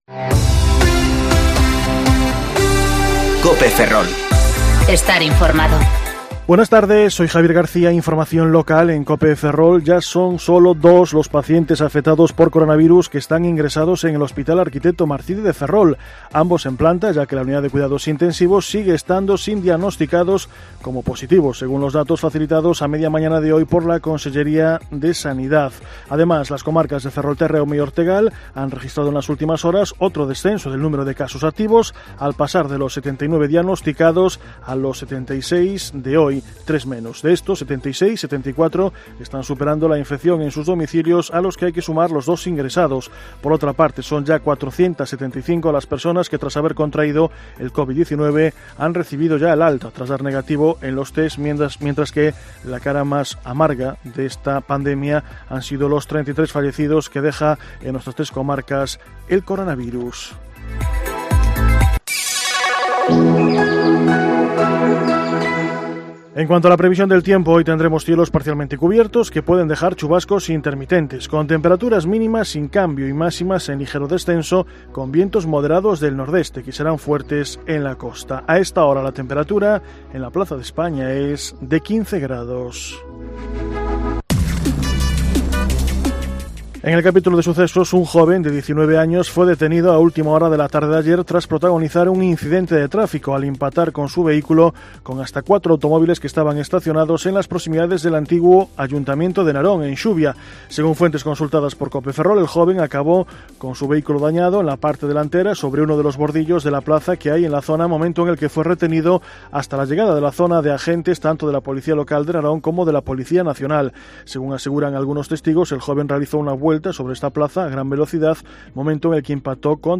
Informativo Mediodía COPE Ferrol - 14/5/2020 (De 14,20 a 14,30 horas)